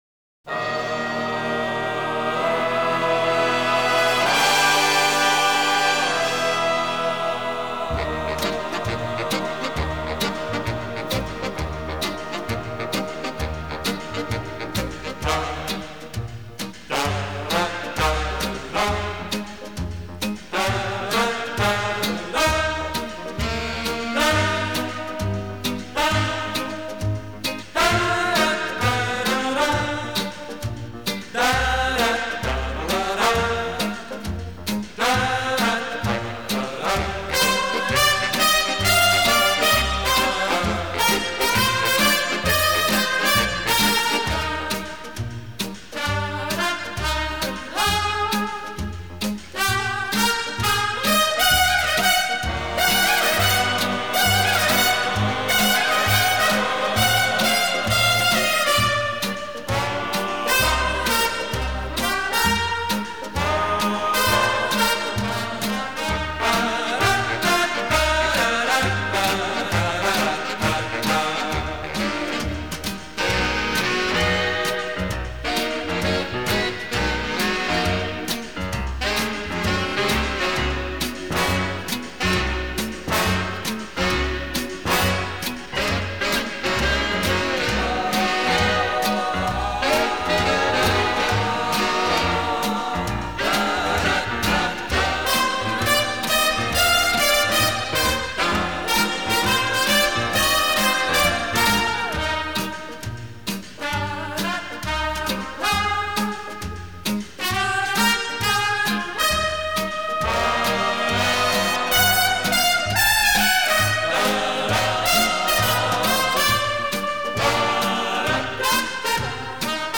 Жанр: Jazz,Easy Listening